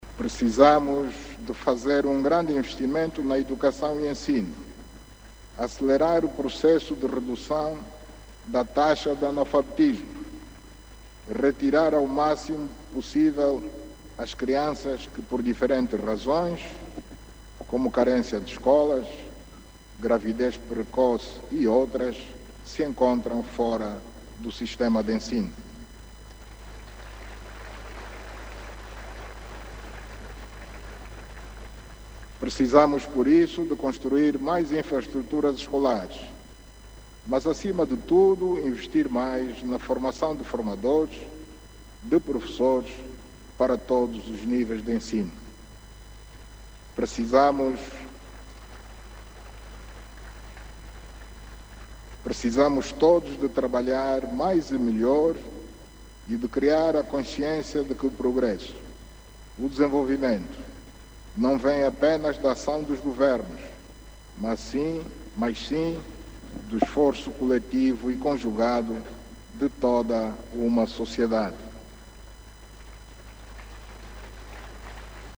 O Presidente da República fez estes pronunciamentos durante o seu discurso à Nação, na Praça da República, em Luanda, onde decorre o acto central das comemorações dos 50 anos da Independência Nacional.